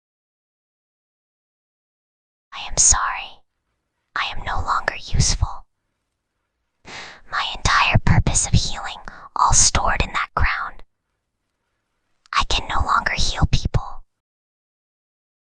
Whispering_Girl_6.mp3